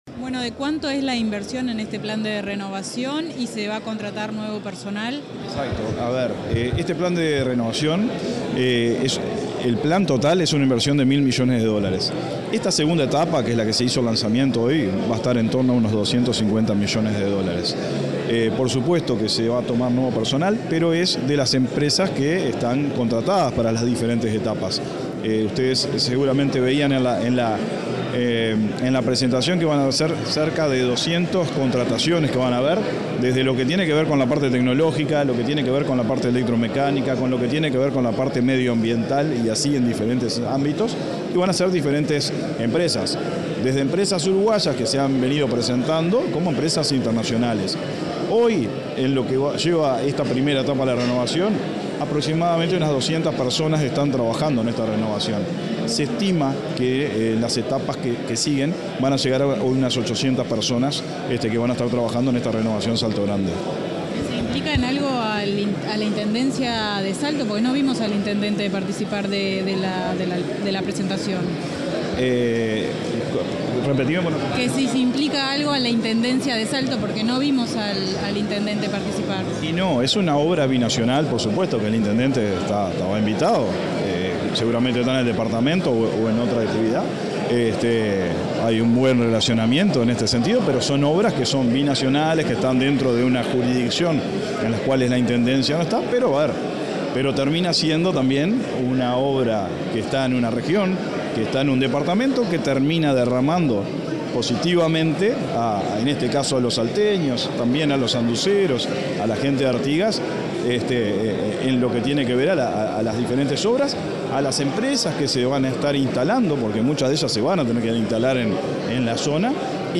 Declaraciones del presidente de la Comisión Técnica Mixta de Salto Grande
Declaraciones del presidente de la Comisión Técnica Mixta de Salto Grande 20/04/2023 Compartir Facebook X Copiar enlace WhatsApp LinkedIn El presidente de la Comisión Técnica Mixta de Salto Grande, Carlos Albisu, dialogó con la prensa luego de participar en la presentación de la segunda etapa del proyecto de renovación y modernización del complejo hidroeléctrico de Salto Grande, realizada este jueves 20 en Montevideo.